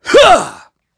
Clause_ice-Vox_Attack3.wav